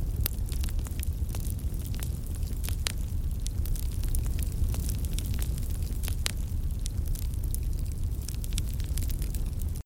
FireIdleLoop.wav